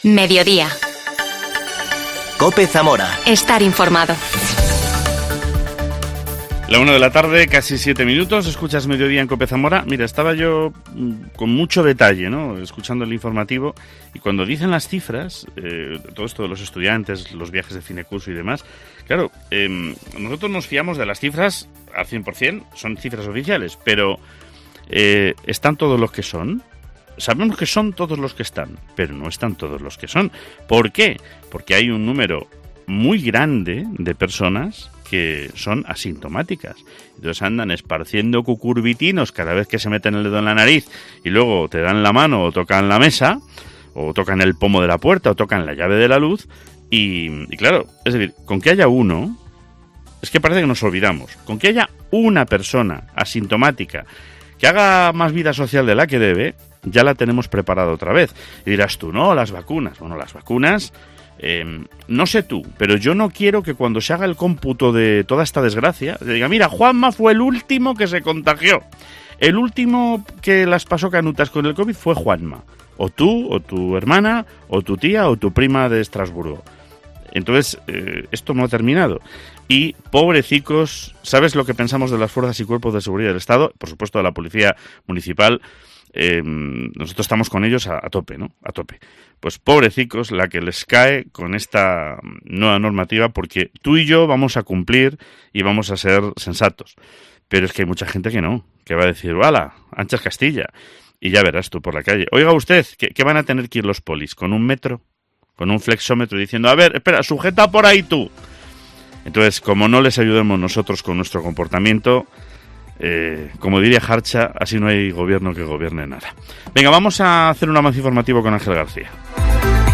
Escuchamos al director general de Patrimonio Cultural, Gumersindo Bueno.